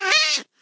sounds / mob / cat / hitt2.ogg
hitt2.ogg